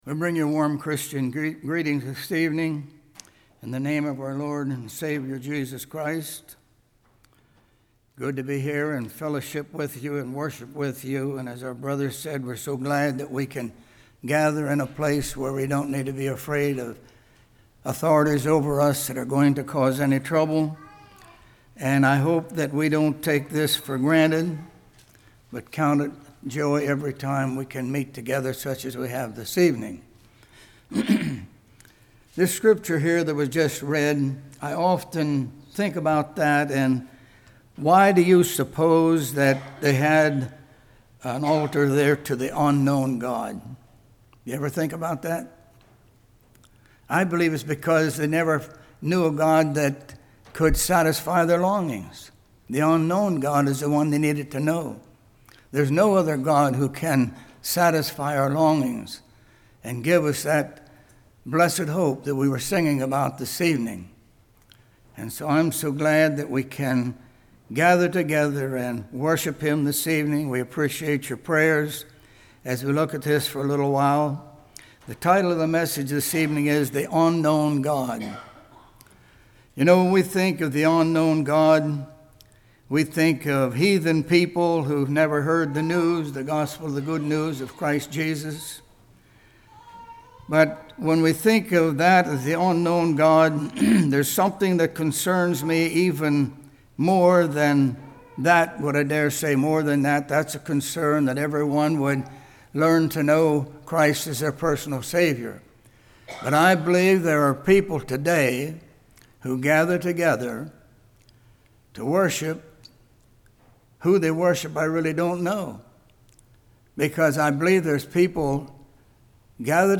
Acts 17:16-34 Service Type: Evening Resurrection of life Resurrection of damnation Obedience « Walk in the Light What Is Your Spiritual Temperature?